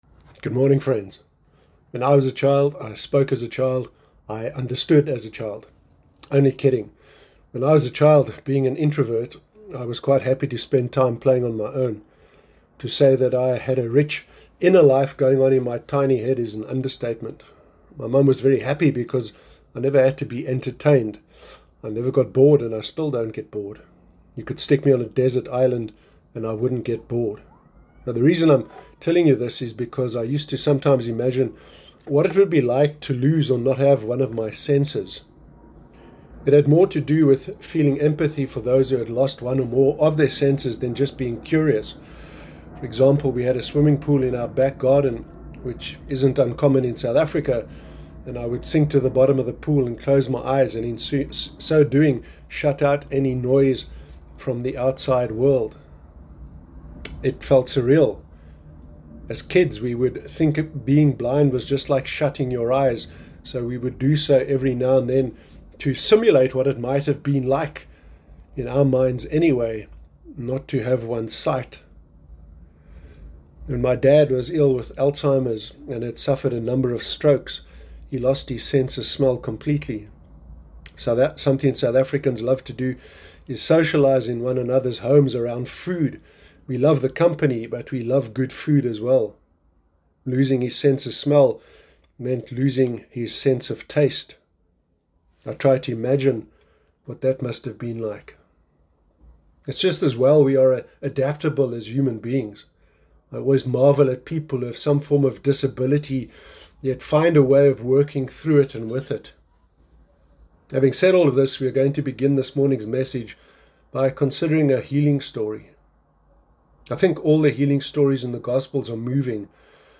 Sermon Sunday sermon